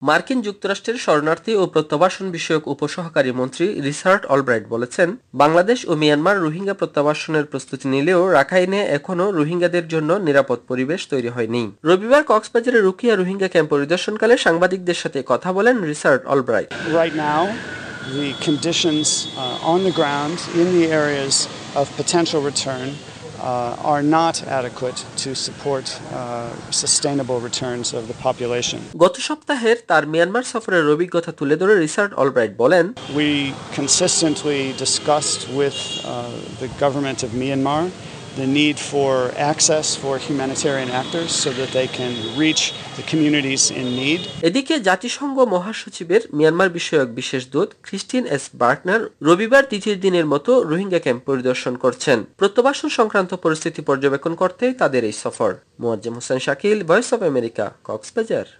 কক্সবাজার থেকে
প্রতিবেদন